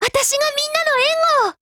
贡献 ） 协议：Copyright，其他分类： 分类:少女前线:UMP9 、 分类:语音 您不可以覆盖此文件。